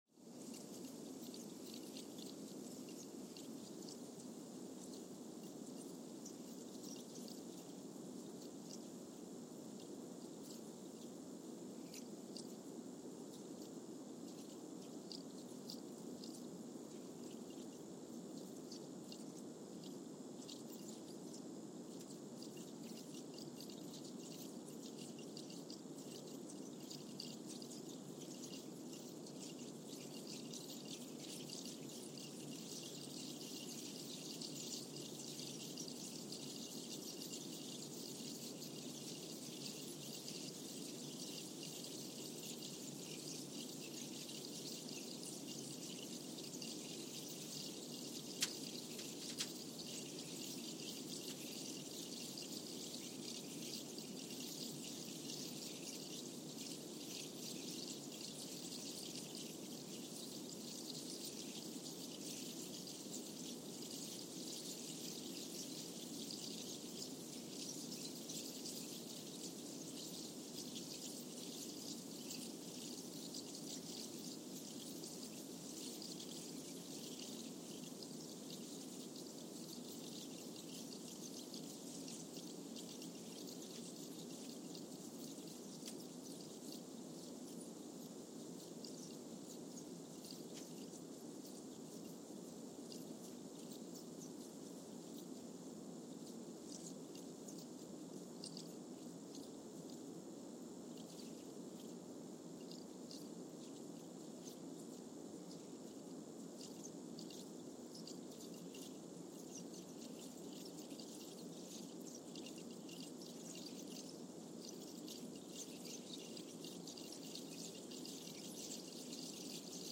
San Juan, Puerto Rico (seismic) archived on February 3, 2023
Station : SJG (network: IRIS/USGS) at San Juan, Puerto Rico
Sensor : Trillium 360
Speedup : ×1,000 (transposed up about 10 octaves)
Loop duration (audio) : 05:45 (stereo)
SoX post-processing : highpass -2 90 highpass -2 90